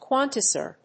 quantizer.mp3